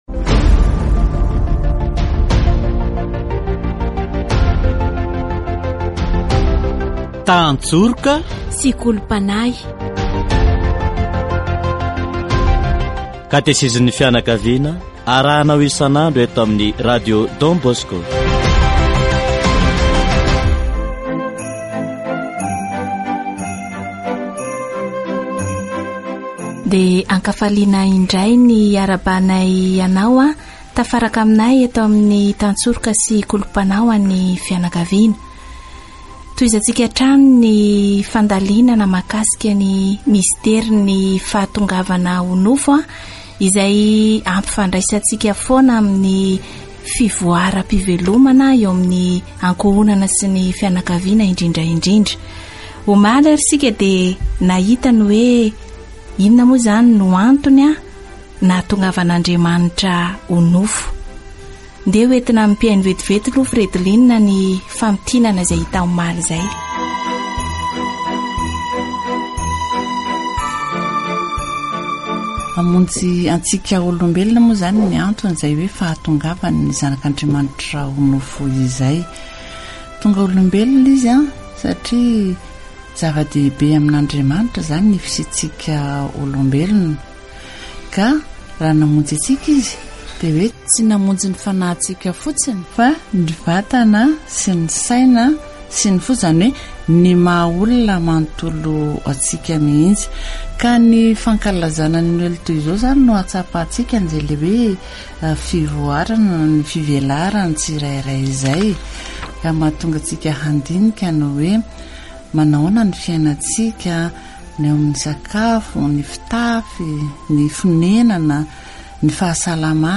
Category: Deepening faith